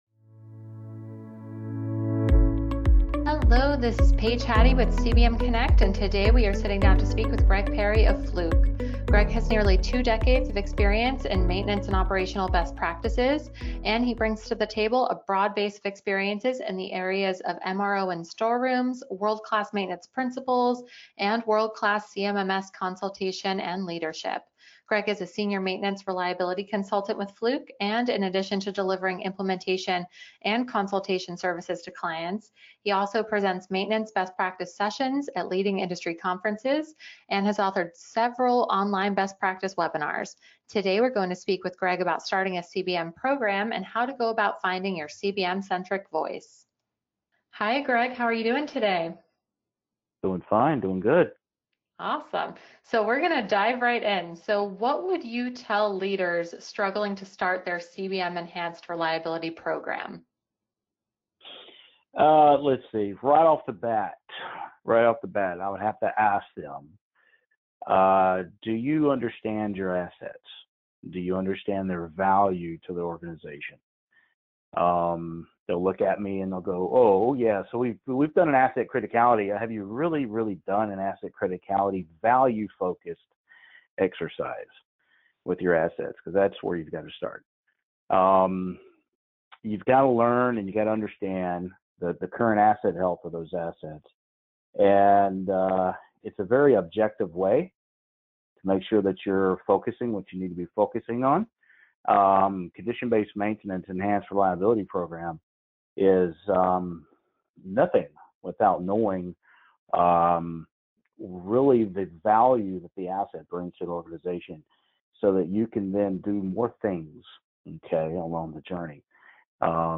MOBIUS CONNECT Interviews Finding Your CBM-Centric Voice May 02 2019 | 00:10:07 1x 00:00 / 00:10:07 Subscribe Share Spotify RSS Feed Share Link Embed